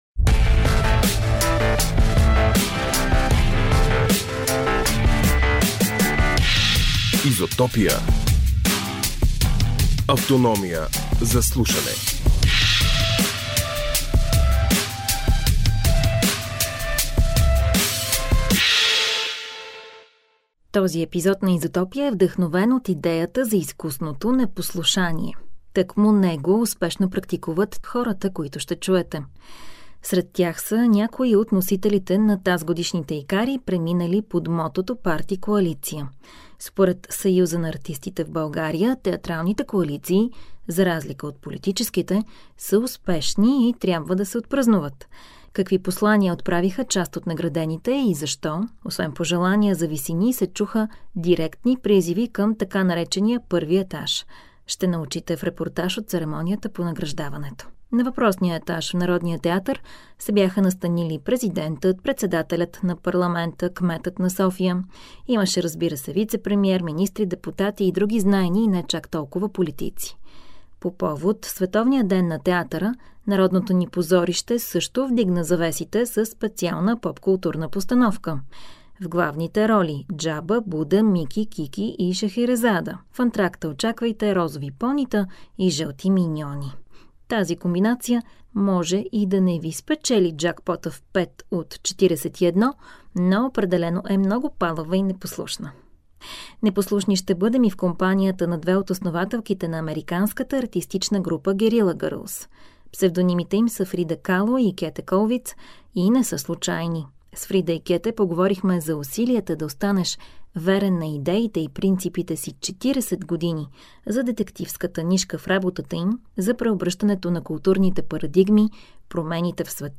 Ще научите в репортаж от церемонията по награждаването.